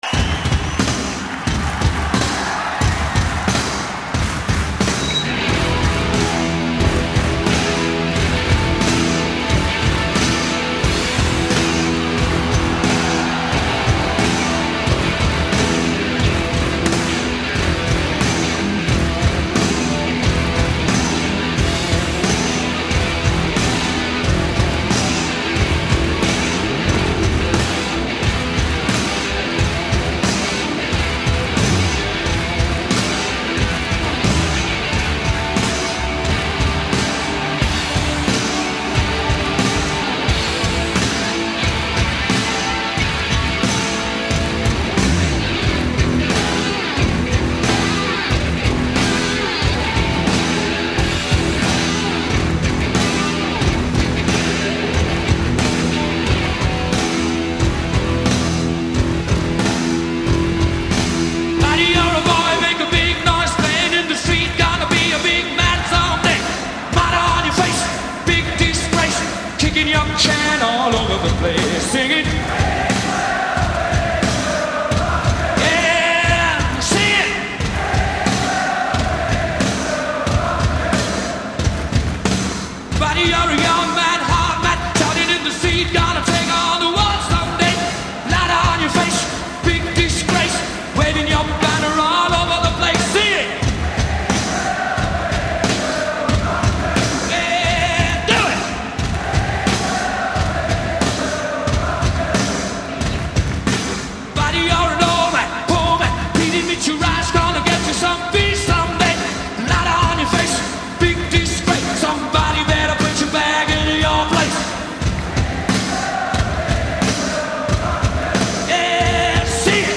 صدای افسانه ای